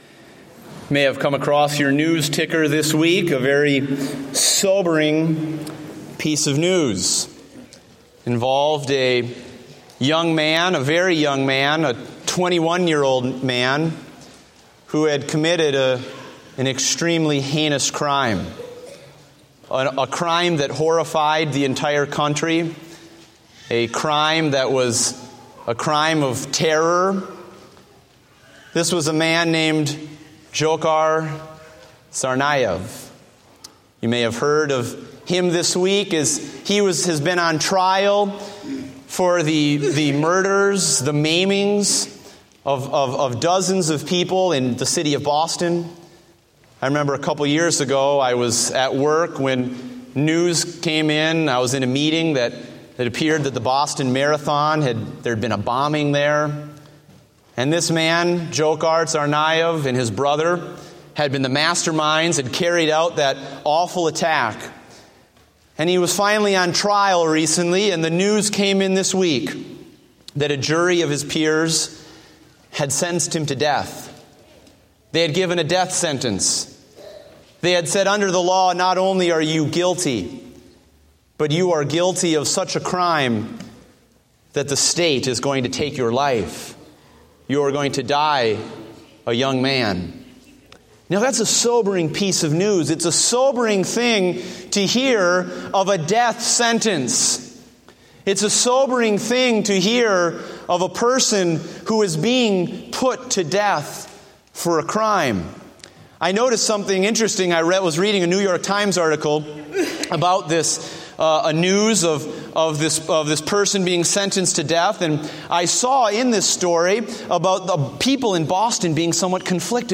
Date: May 17, 2015 (Morning Service)